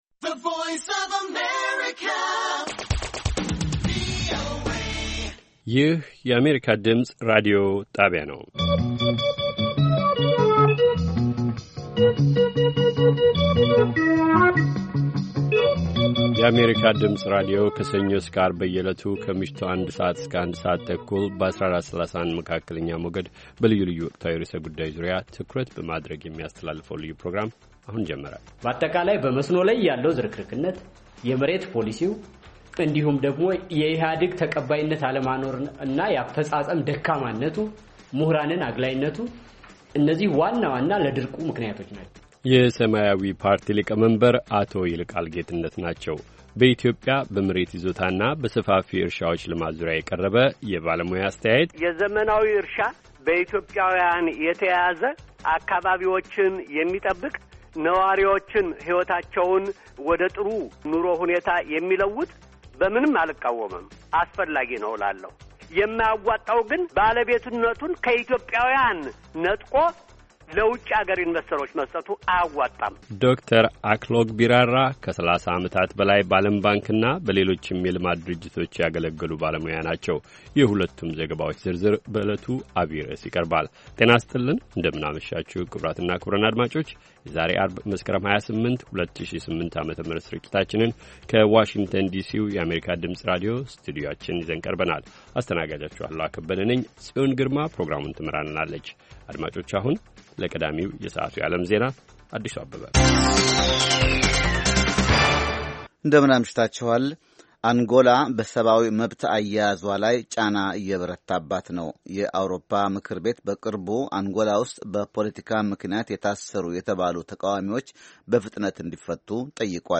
ከምሽቱ አንድ ሰዓት የአማርኛ ዜና